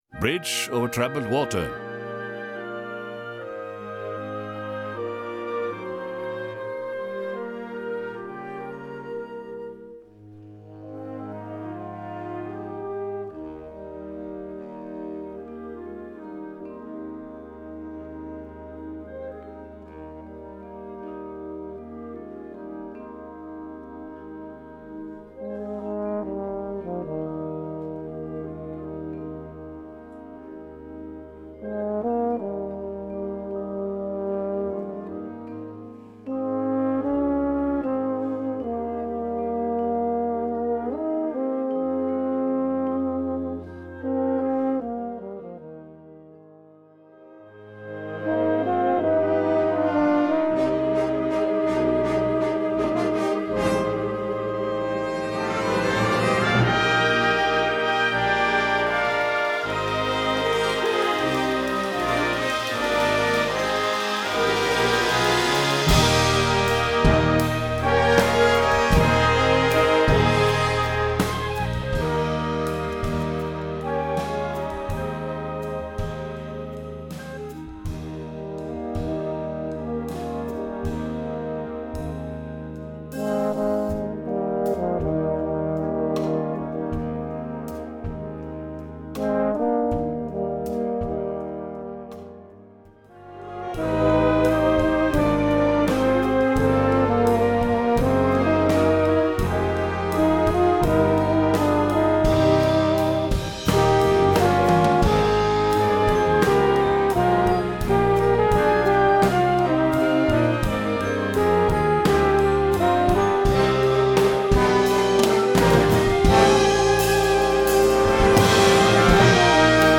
Gattung: Solo für Gesang und Blasorchester
Besetzung: Blasorchester